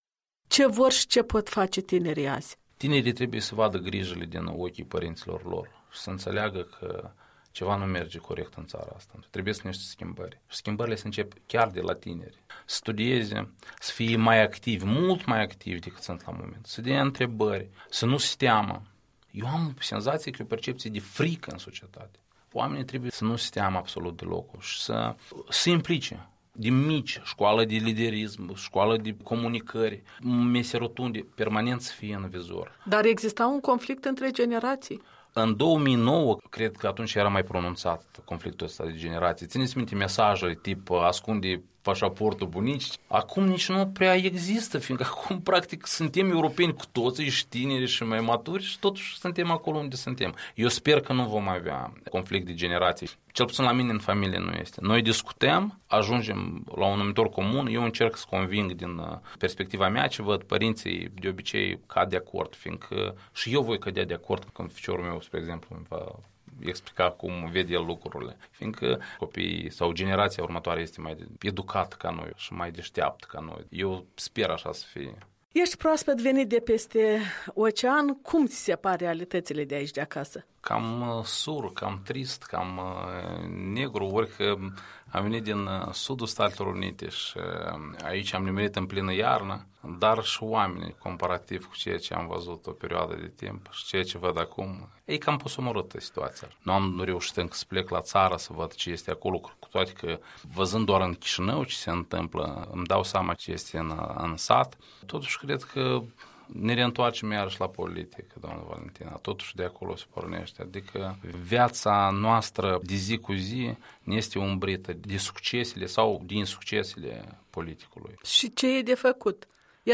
Un interviu cu un politolog și jurist, întors din Statele Unite după șase ani de muncă.